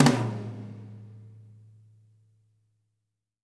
-TOM 2L   -L.wav